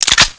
deagle_cin.wav